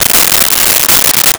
Metal Zipper 03
Metal Zipper 03.wav